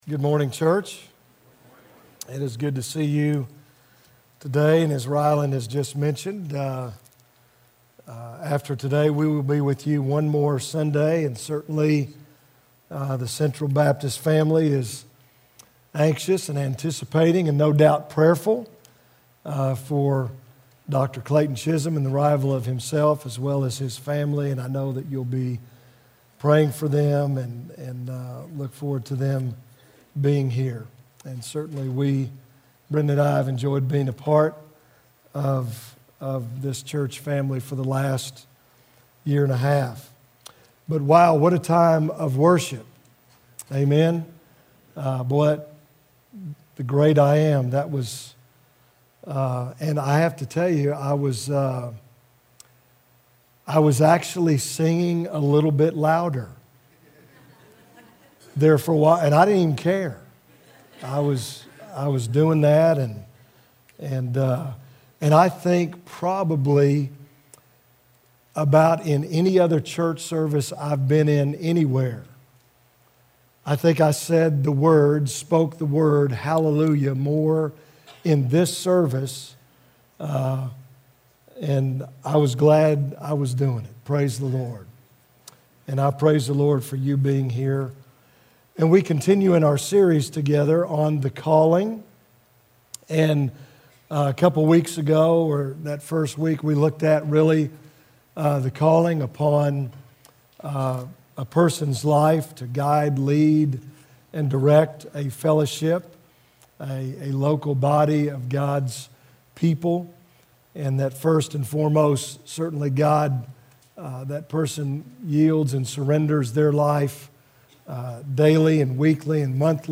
March-8th-Service.mp3